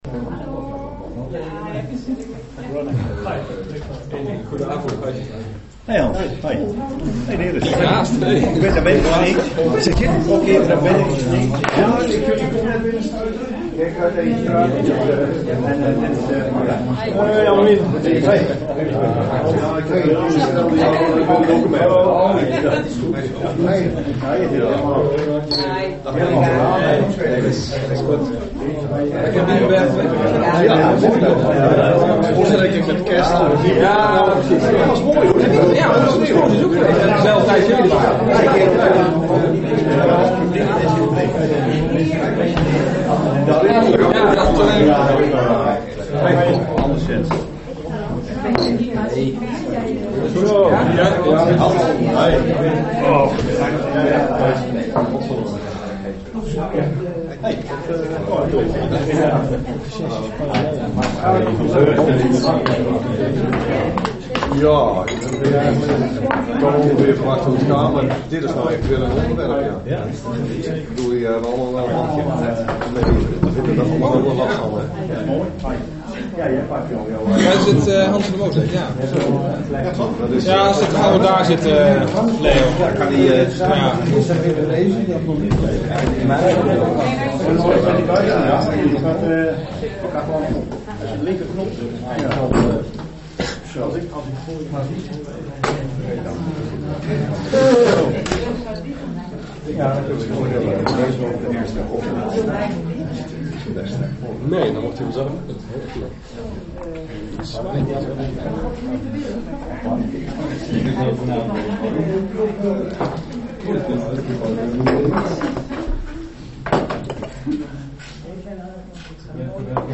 Locatie S02, gemeentehuis Elst Toelichting Informatiebijeenkomst van College: voortgang van het Bresdo-rapport.